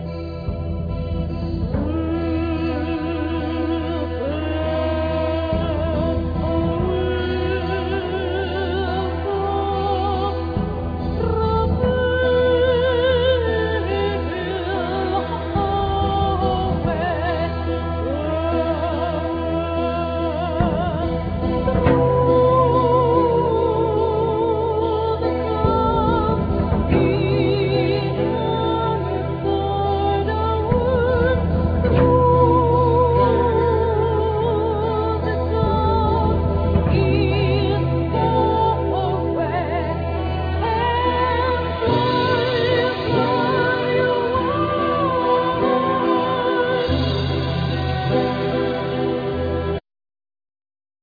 Keyboards,Guitar Vocals,Percussions Violin Percussions